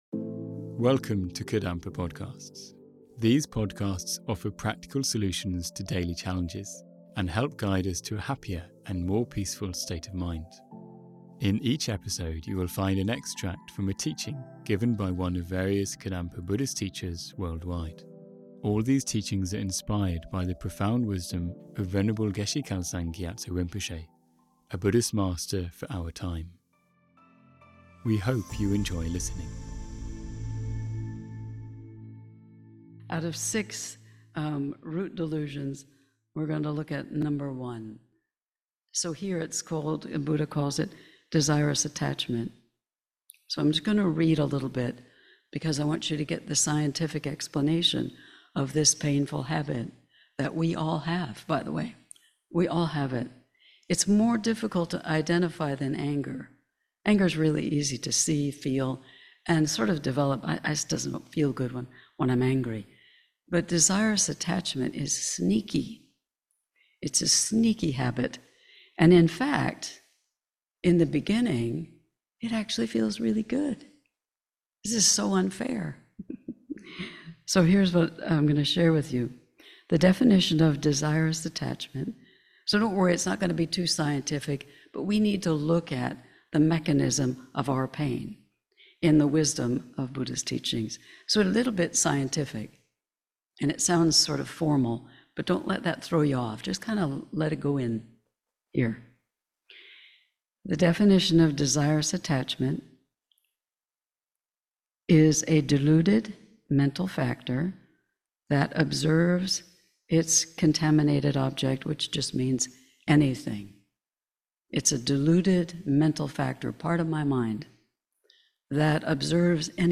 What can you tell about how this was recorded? This is an extract of a talk given at KMC Florida based on the book How to Understand the Mind by Geshe Kelsang Gyatso